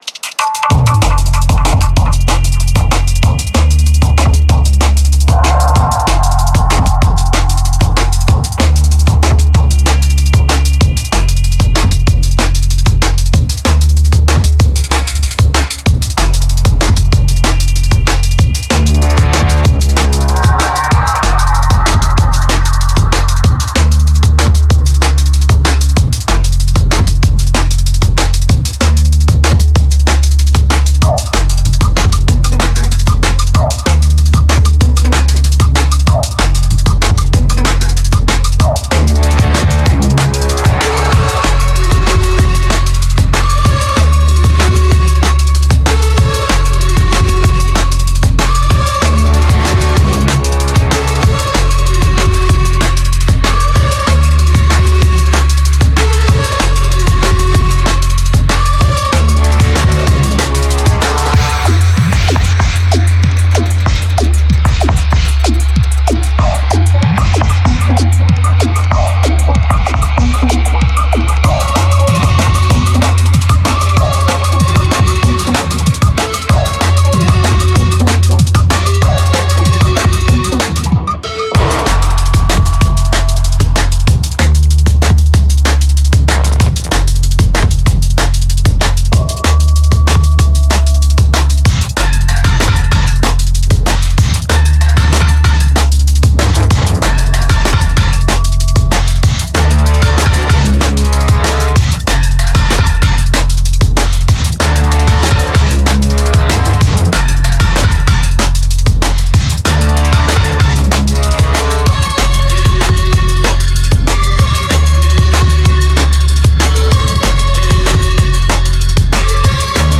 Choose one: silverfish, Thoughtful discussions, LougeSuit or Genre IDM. Genre IDM